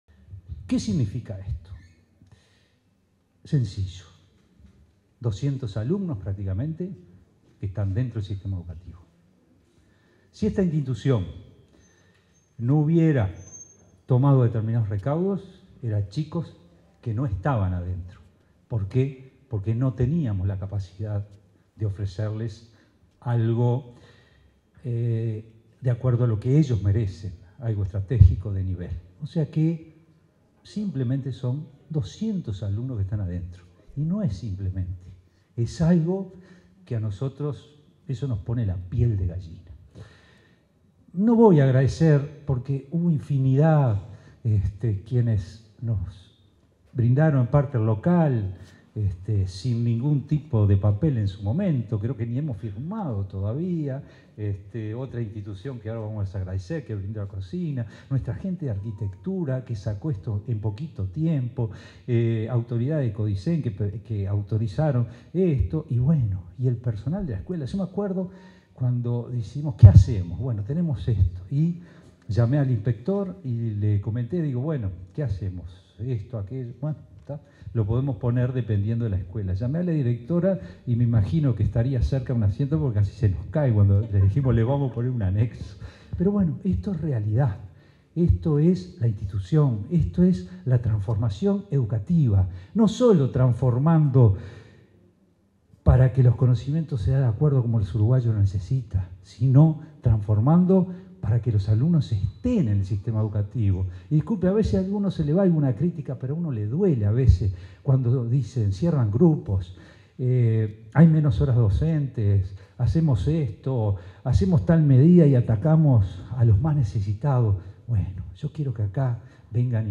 Inauguración de anexo de Escuela de Hotelería de UTU en Montevideo 15/05/2023 Compartir Facebook Twitter Copiar enlace WhatsApp LinkedIn En el marco de la ceremonia de inauguración de un anexo de la Escuela de Hotelería de UTU en Montevideo, este 15 de mayo, se expresaron el director general de Educación Técnico Profesional, Juan Pereyra, y el presidente de la Administración Nacional de Educación Pública (ANEP), Robert Silva.